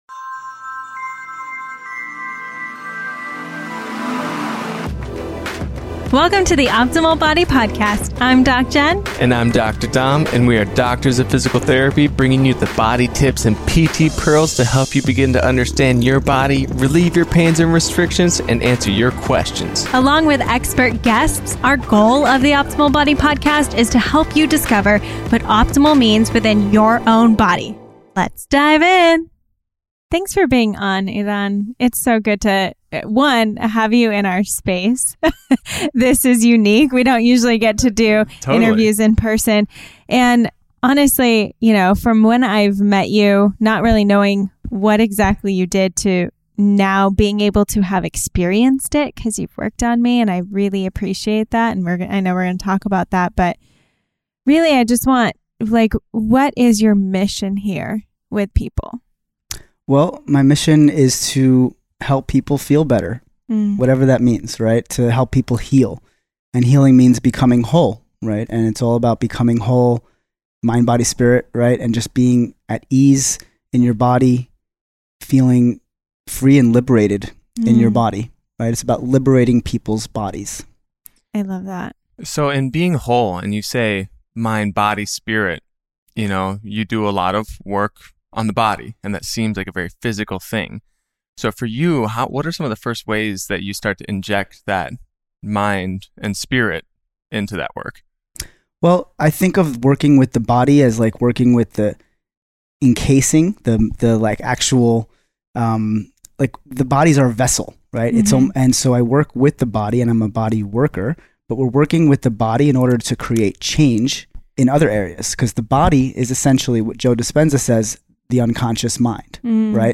You don’t want to miss out on this in depth and healing conversation, for it provides the tools necessary to start your own optimal body journey.